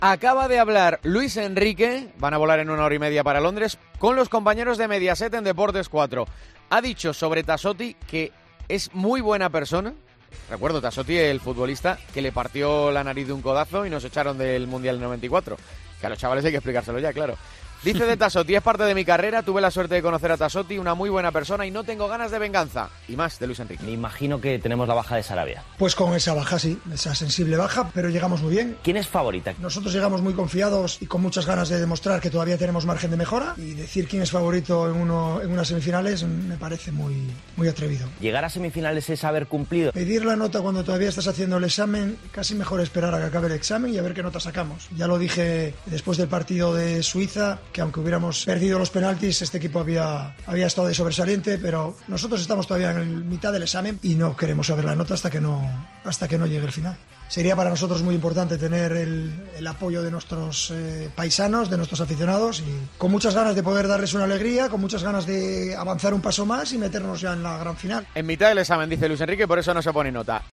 AUDIO: El entrenador de la Selección española ha hablado en Mediaset sobre el partido de semifinales de este martes frente a Italia.